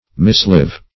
mislive - definition of mislive - synonyms, pronunciation, spelling from Free Dictionary Search Result for " mislive" : The Collaborative International Dictionary of English v.0.48: Mislive \Mis*live"\, v. i. To live amiss.